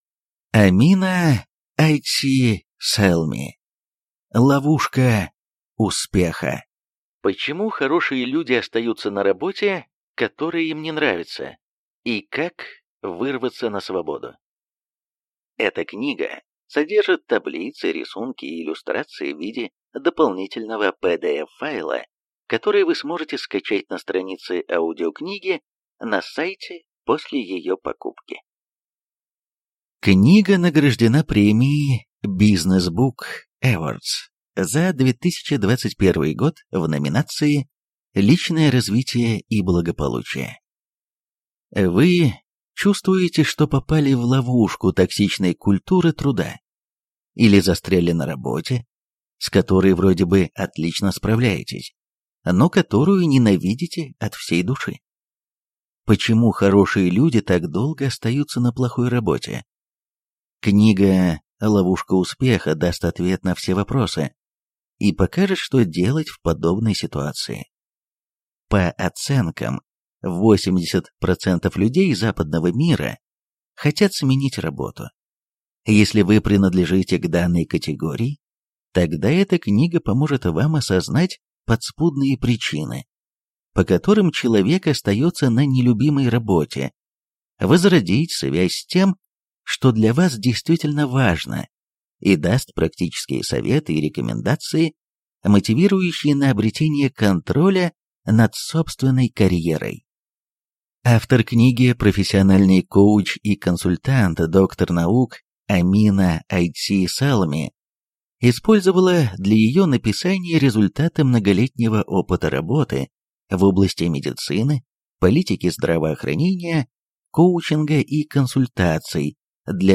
Аудиокнига Ловушка успеха. Почему хорошие люди остаются на работе, которая им не нравится, и как вырваться на свободу | Библиотека аудиокниг